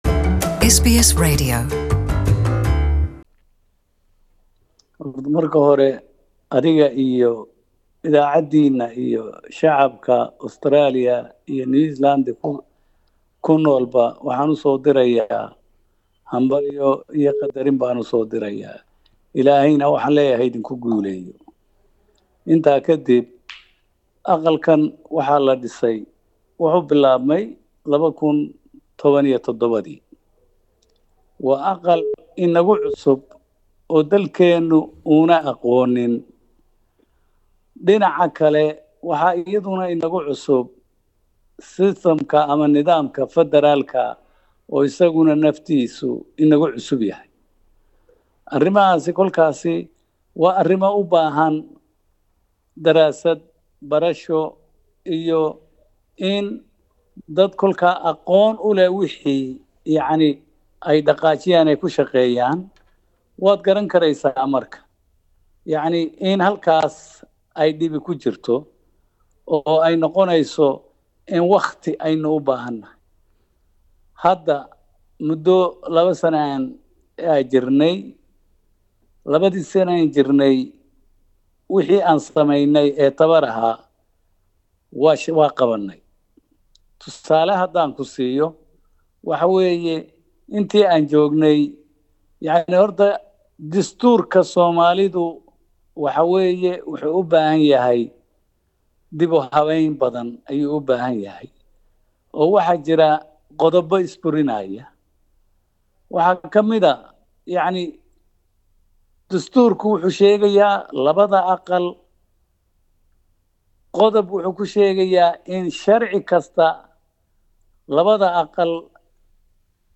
Interview: Speaker of Somali Senate Abdi Hashi